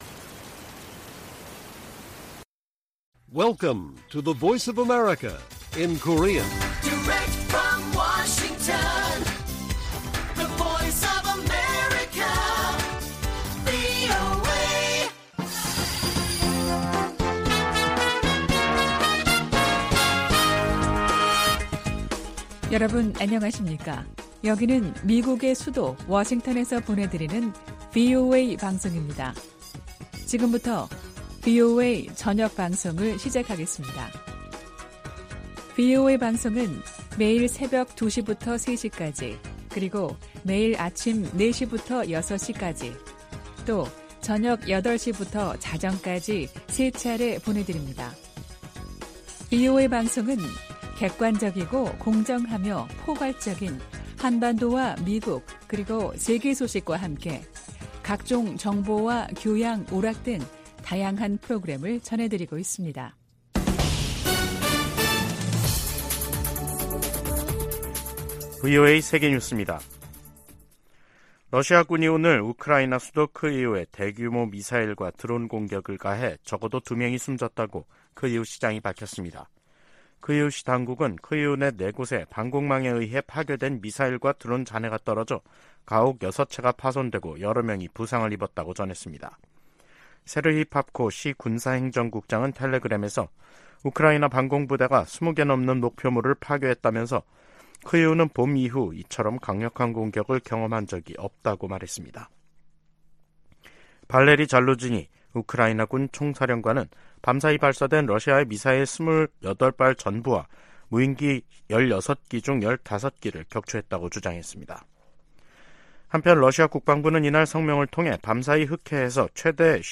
VOA 한국어 간판 뉴스 프로그램 '뉴스 투데이', 2023년 8월 30일 1부 방송입니다. 미국과 한국, 일본은 한반도 사안을 넘어 국제적으로 안보협력을 확대하고 있다고 백악관 조정관이 말했습니다. 미 국방부는 위성 발사 같은 북한의 모든 미사일 활동에 대한 경계를 늦추지 않을 것이라고 강조했습니다. 국제 핵실험 반대의 날을 맞아 여러 국제 기구들이 북한의 핵과 미사일 개발을 규탄했습니다.